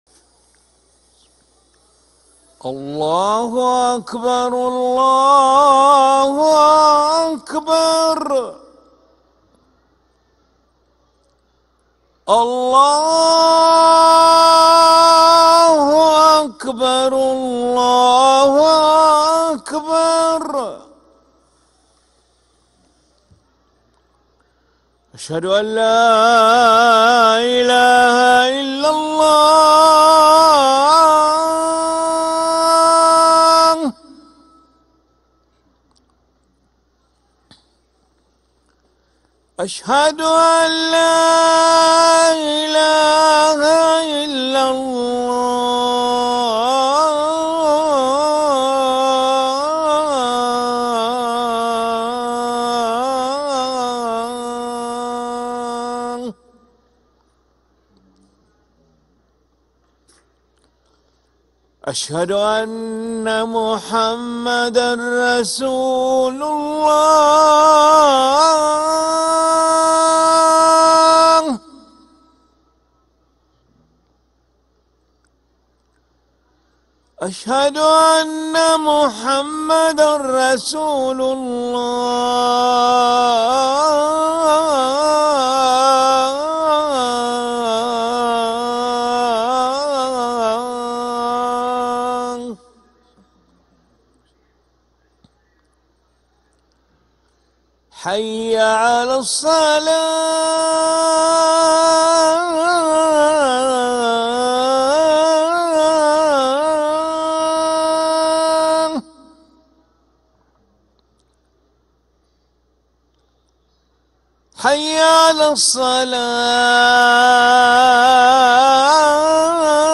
أذان العشاء للمؤذن علي ملا الأحد 29 جمادى الأولى 1446هـ > ١٤٤٦ 🕋 > ركن الأذان 🕋 > المزيد - تلاوات الحرمين